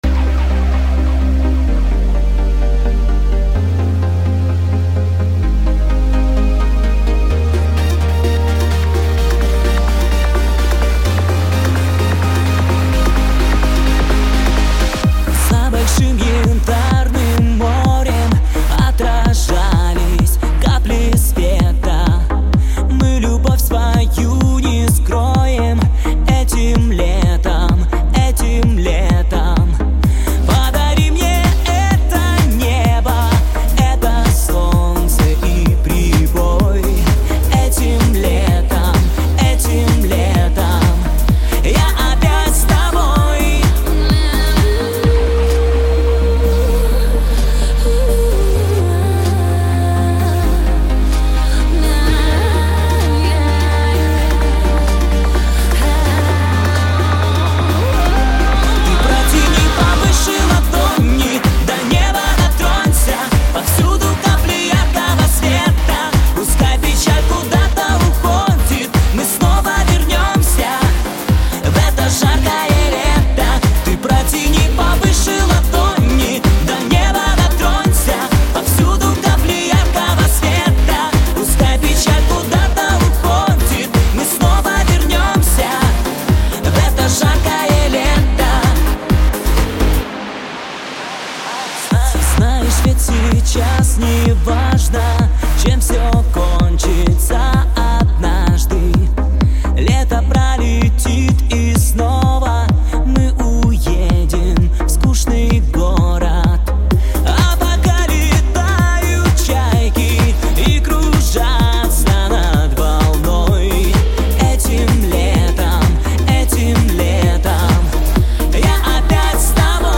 Подборка зажигательной музыки этого месяца